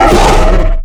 giant_hurt_0.ogg